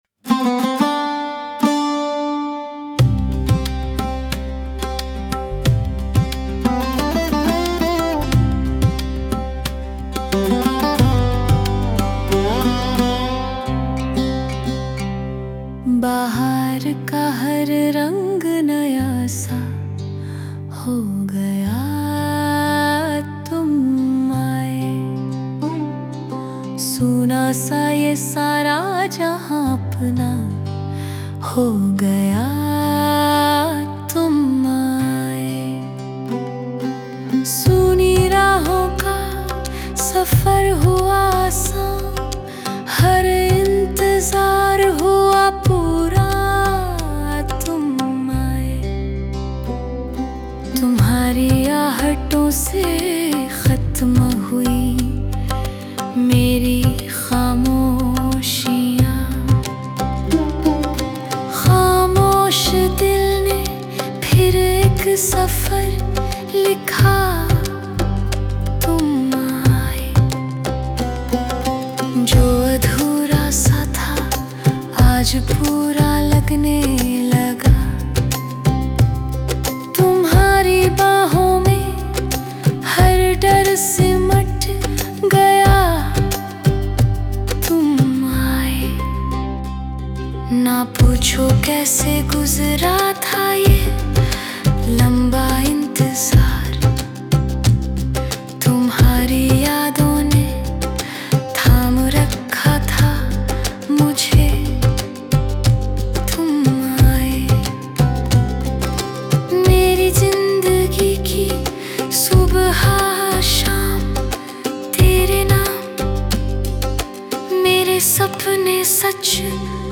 Tum Aaye: A ghazal
Tum Aaye is a warm, uplifting ghazal that keeps a single, heartfelt mood: the world brightens when the beloved arrives.
(This gets repeated after a soulful interlude.)